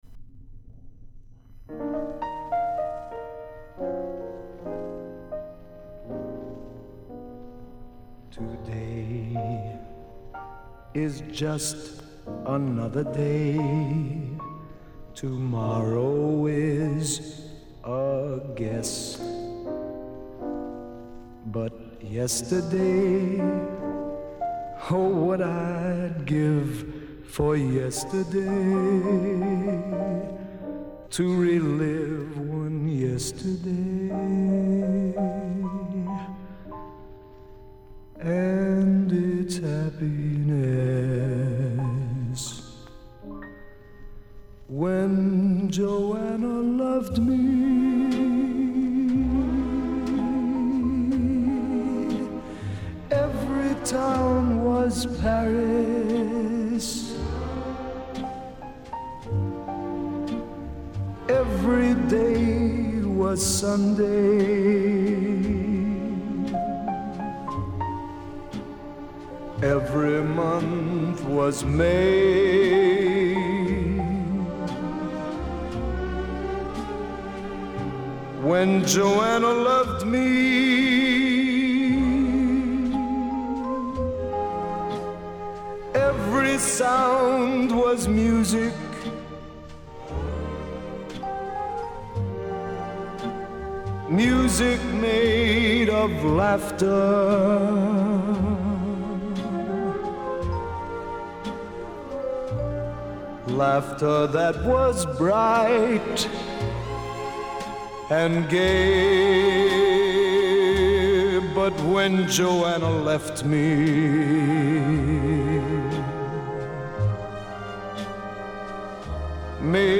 Жанры Свинг
Поп-музыка
Джаз